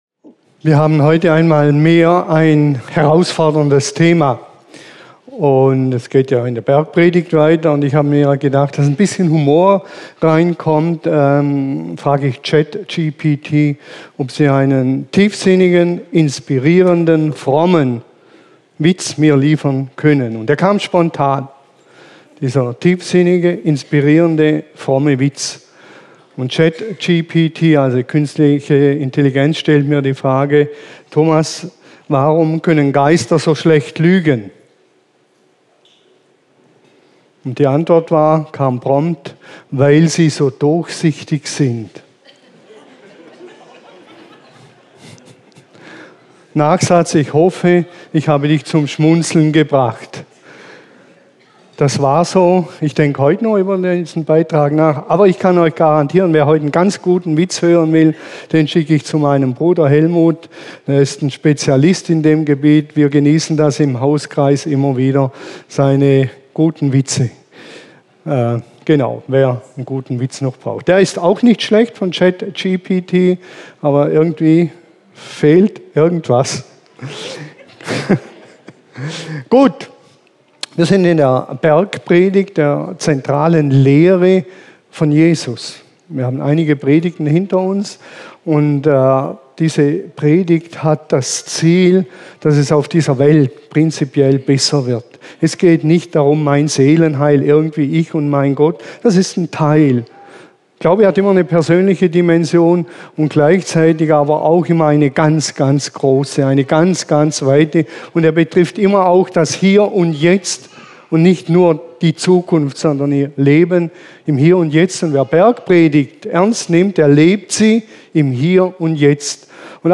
Predigt als Audio